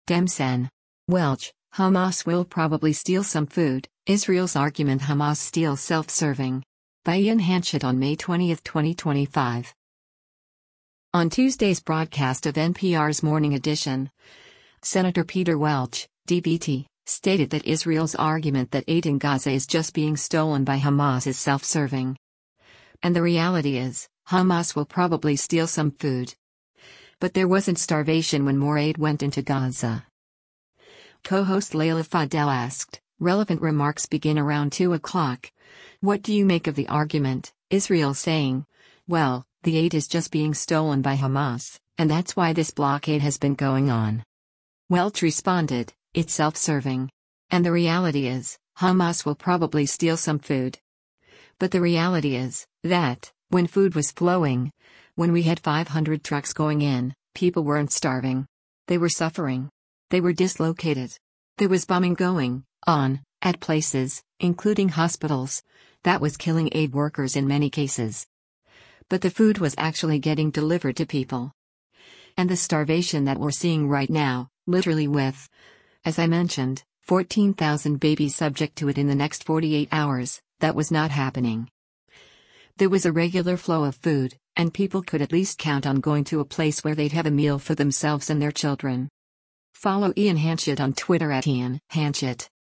On Tuesday’s broadcast of NPR’s “Morning Edition,” Sen. Peter Welch (D-VT) stated that Israel’s argument that aid in Gaza is just being stolen by Hamas is “self-serving. And the reality is, Hamas will probably steal some food.” But there wasn’t starvation when more aid went into Gaza.